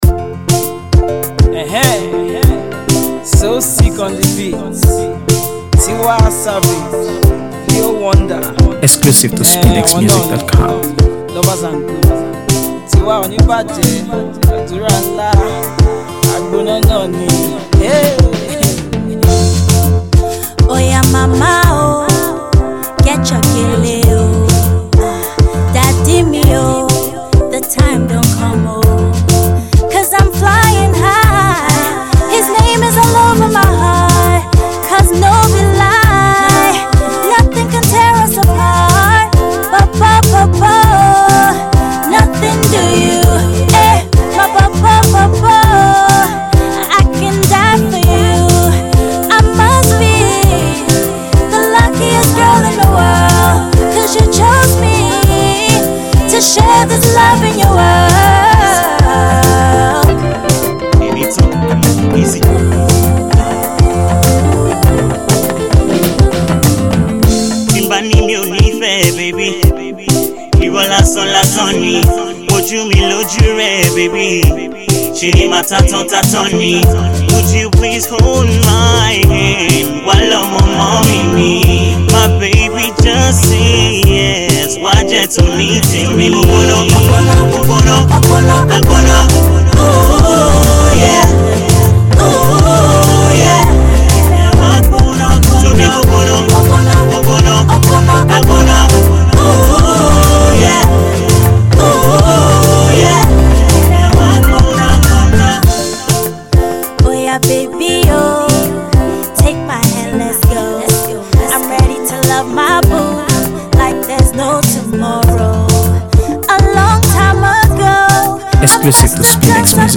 AfroBeats | AfroBeats songs
love song